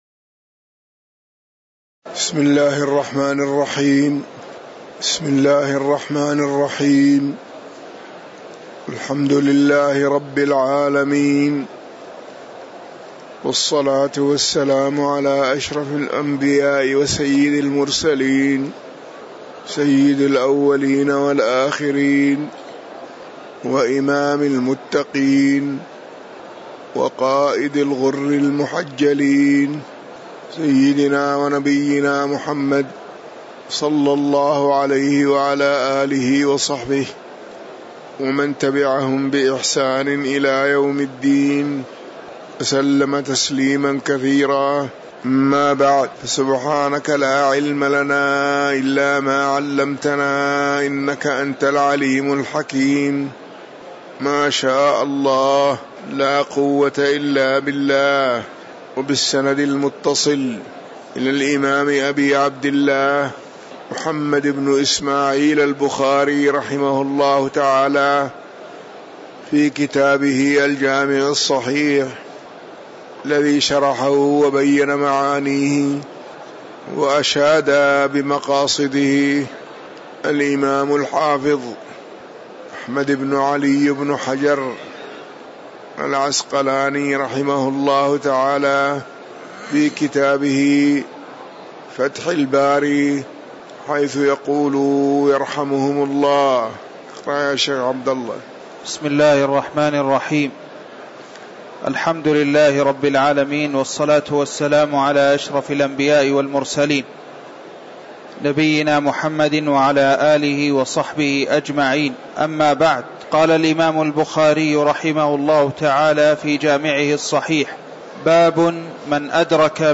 تاريخ النشر ٢١ ربيع الأول ١٤٤١ هـ المكان: المسجد النبوي الشيخ